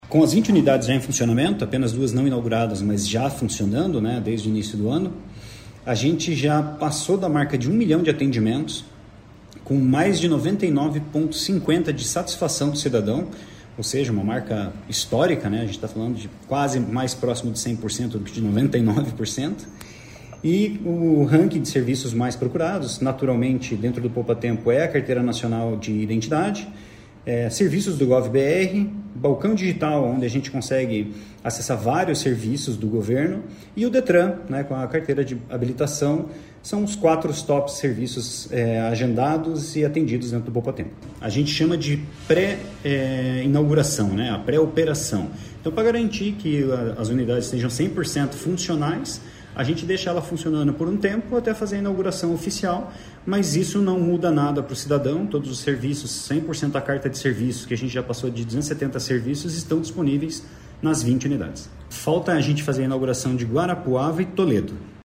Sonora do superintendente-geral de Governança de Serviços e Dados, Leandro de Moura, sobre os 1,1 milhões de atendimentos nos Poupatempos do Paraná